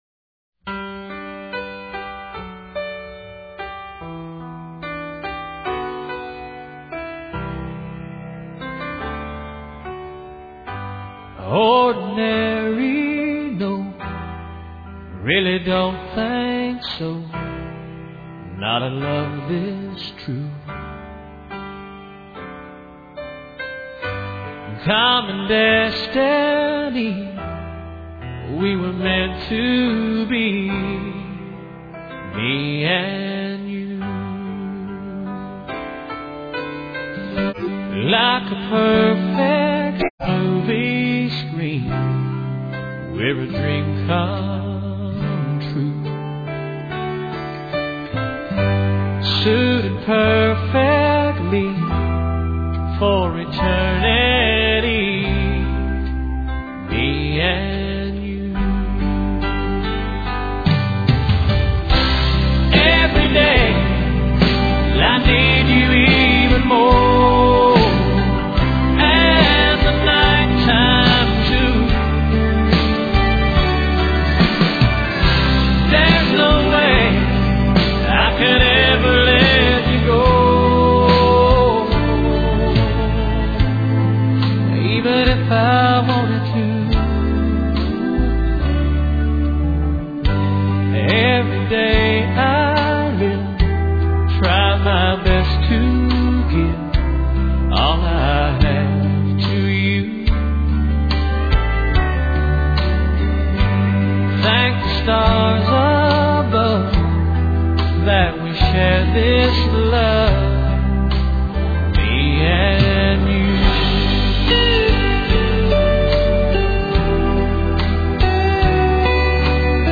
These are some of our favorite slow dance songs.